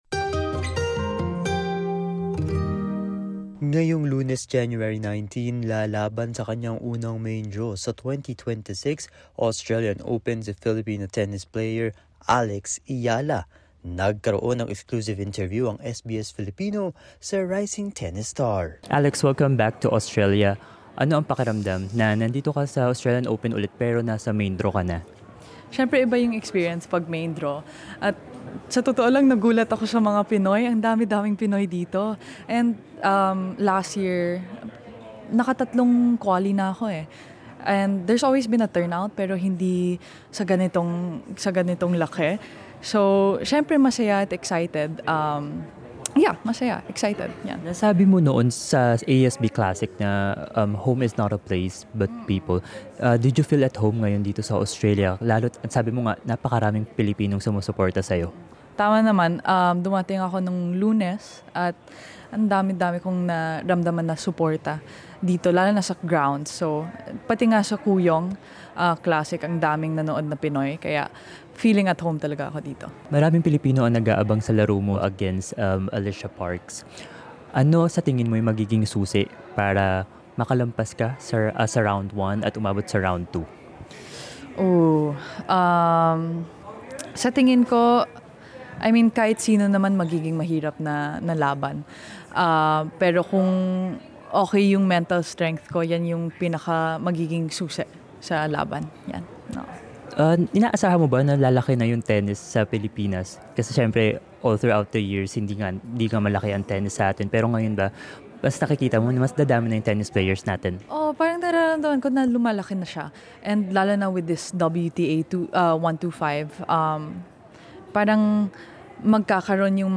Haharap si Alex Eala sa kanyang unang main draw sa Australian Open 2026 ngayong Enero 19. Sa exclusive interview ng SBS Filipino, ibinahagi niya ang kanyang paghahanda at pasasalamat sa suporta ng mga Filipino.